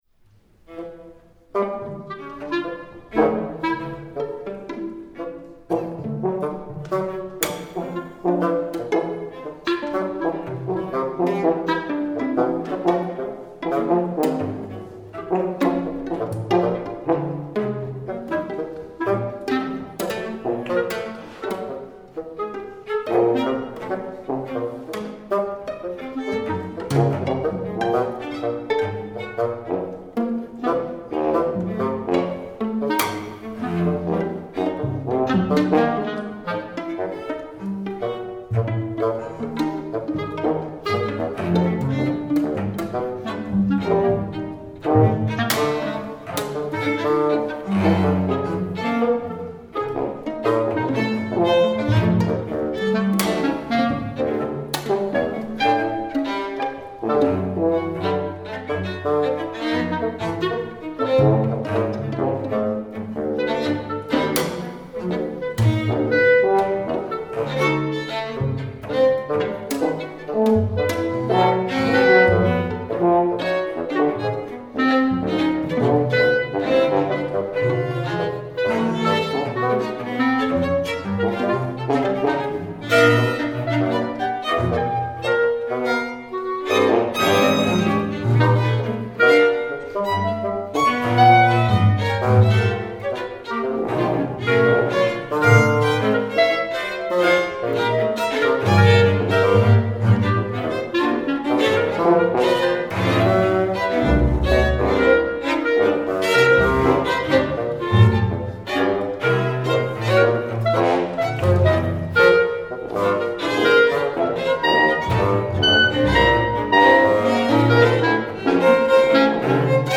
for Octet